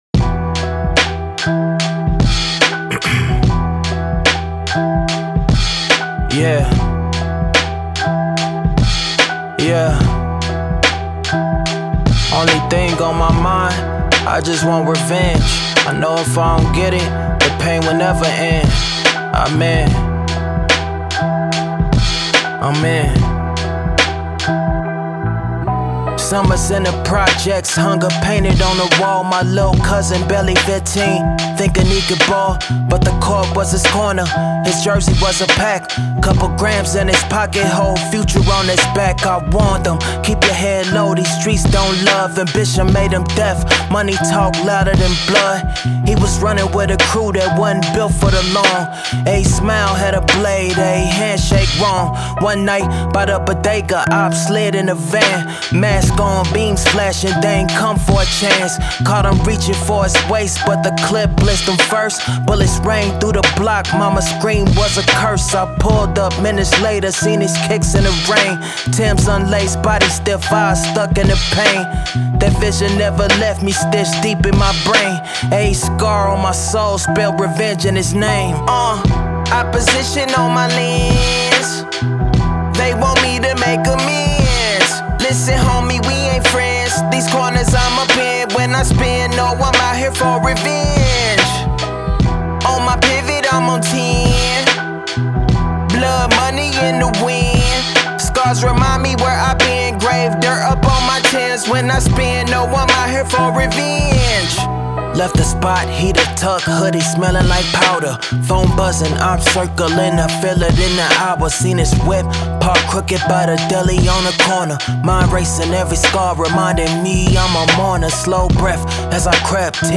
Boom Bap, Hip Hop
Em